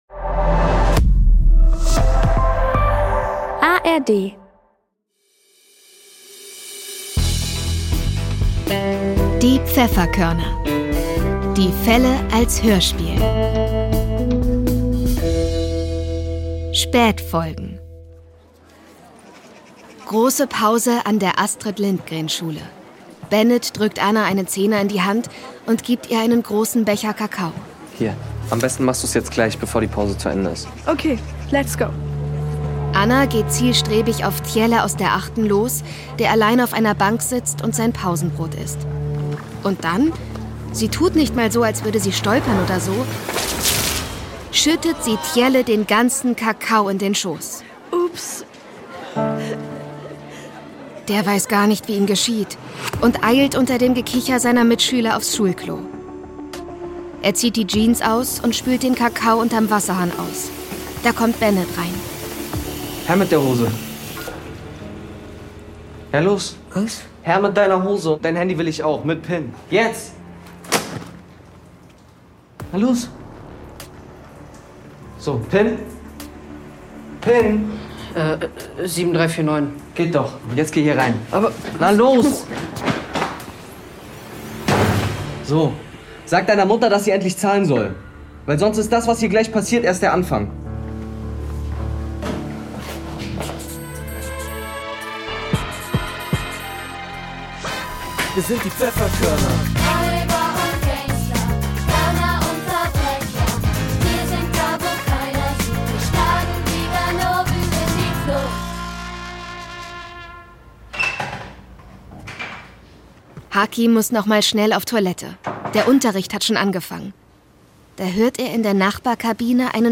Erzählerin